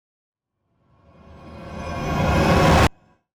Transition Sound Effect Free Download
Transition